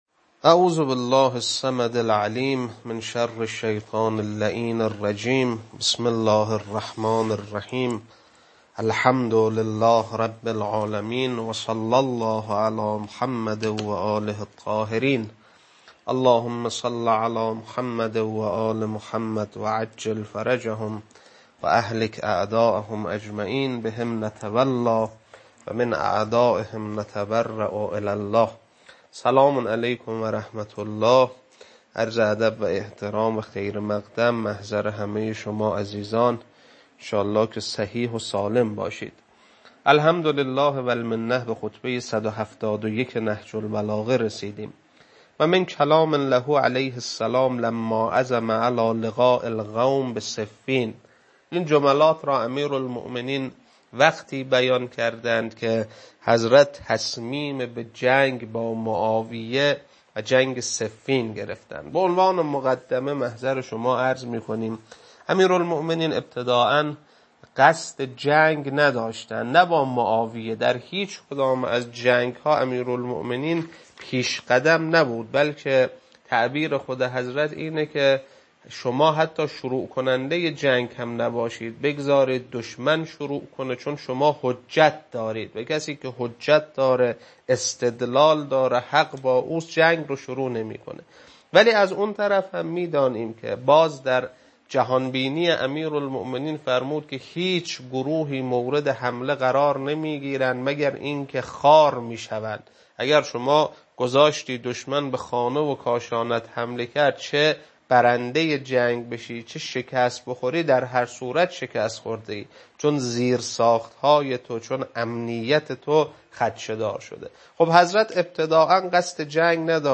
خطبه-171.mp3